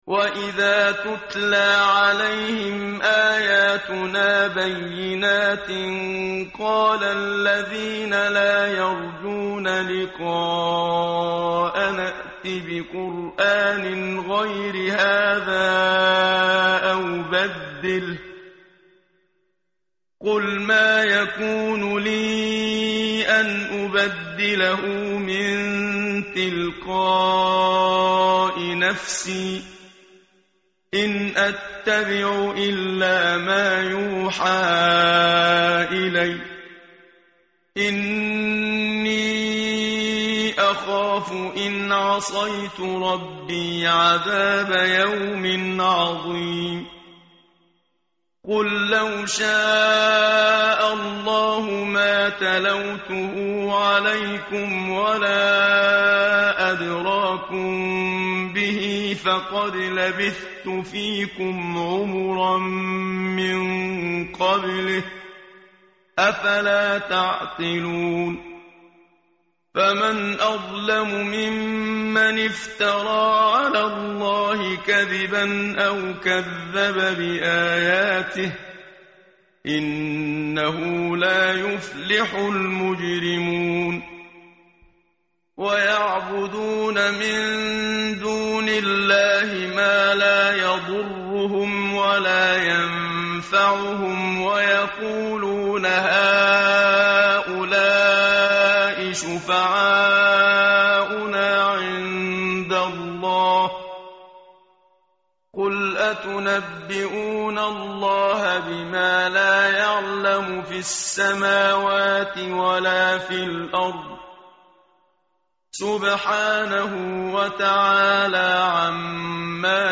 متن قرآن همراه باتلاوت قرآن و ترجمه
tartil_menshavi_page_210.mp3